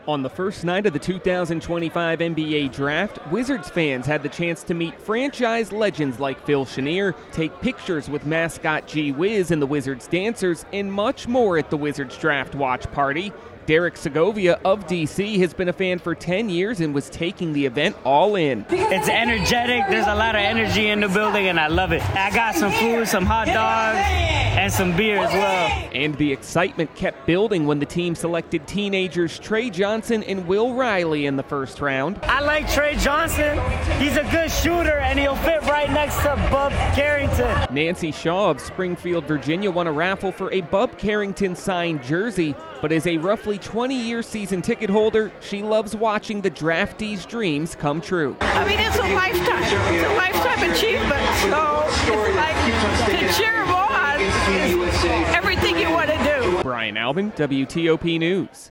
As Washington dealt with the draft process, inside the MGM national Harbor, fans partied the night away while watching the annual event.
2-wizards-draft-party-bal.mp3